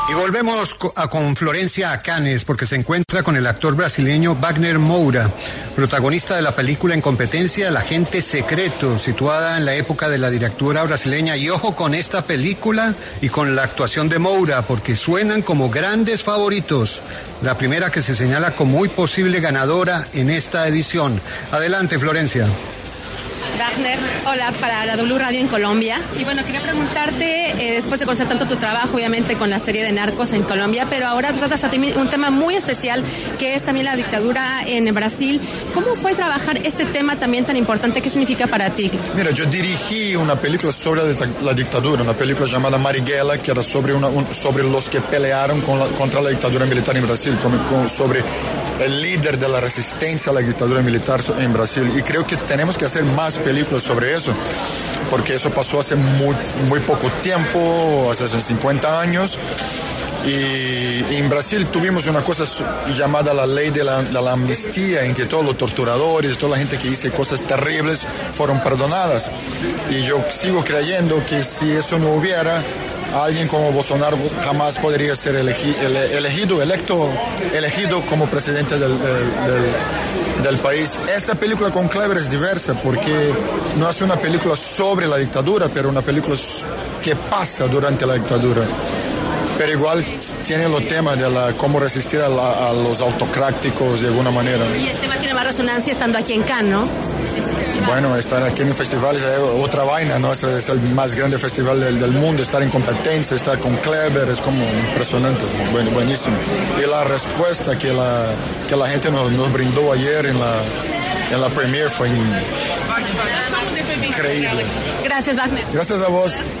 Wagner Moura habló con La W desde el Festival de Cannes y dio detalles de su película.